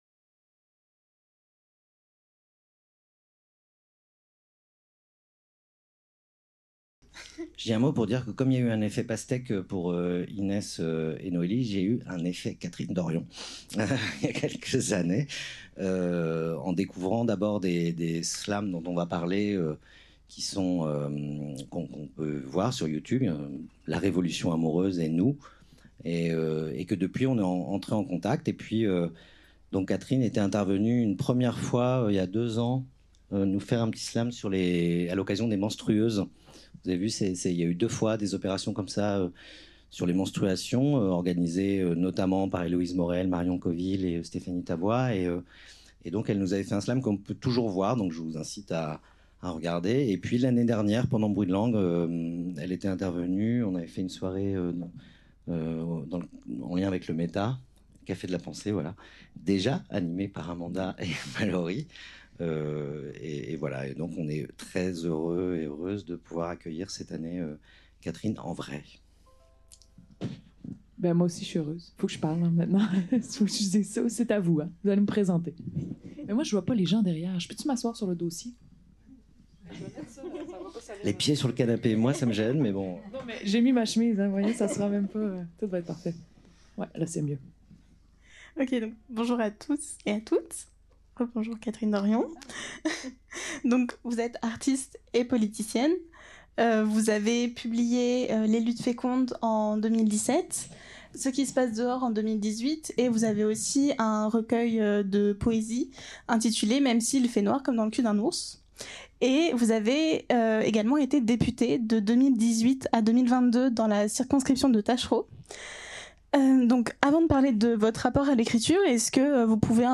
Rencontre avec Catherine Dorion | Canal U
Rencontre avec l'autrice, comédienne et militante politique du Quebec, Catherine Dorion